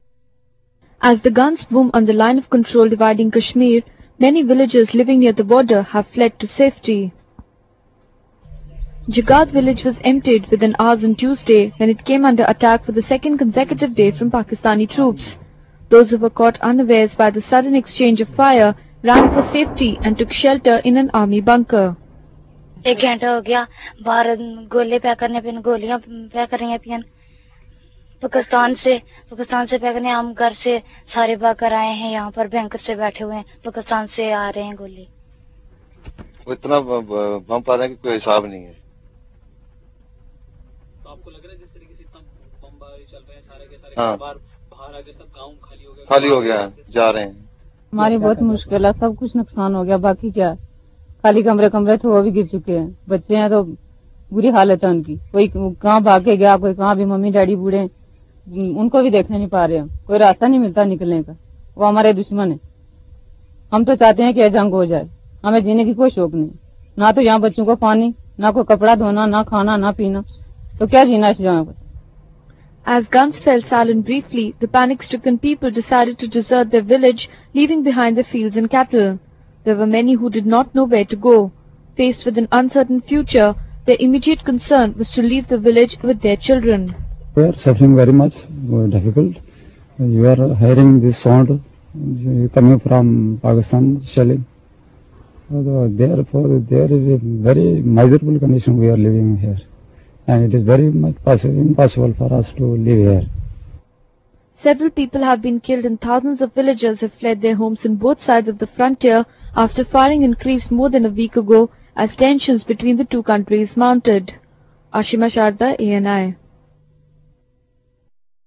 In video: A village in the Rajouri sector of Jammu empties within hours following heavy shelling by Pakistani troops.